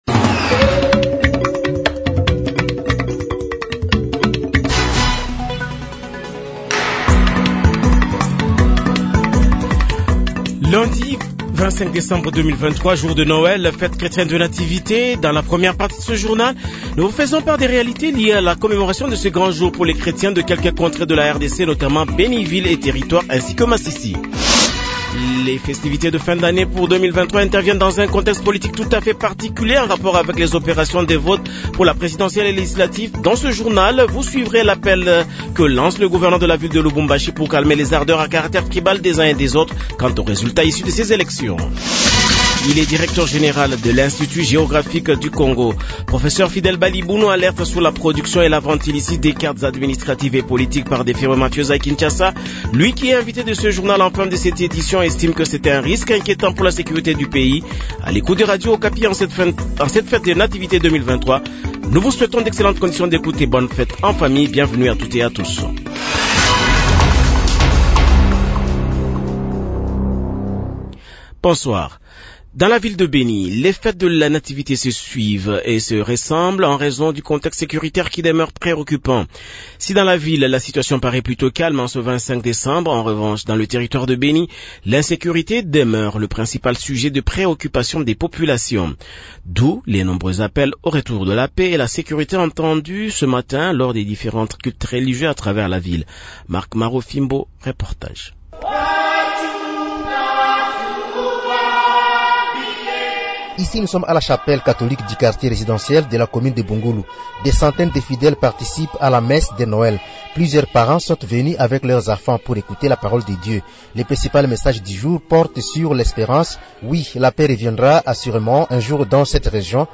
Journal Soir
Bukavu : Quid du sens de la fête de Noel, les habitants de Bukavu s’expriment/vox pop